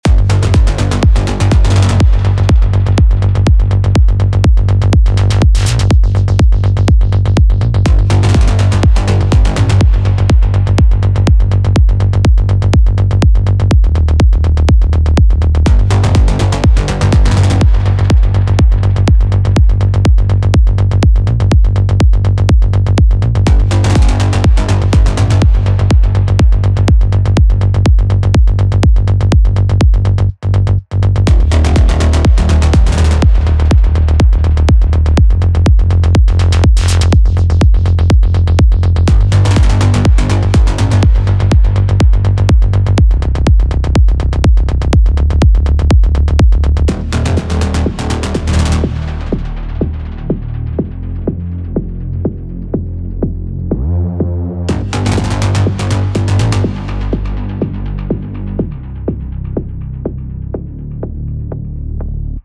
Тут вроде парень разобрал в эбелтоне "Расчипитель" для голоса я подобрал свой более прикольный, .но синт пока не нашёл именно синтезатор Вообще это какой то FM синт по звуку...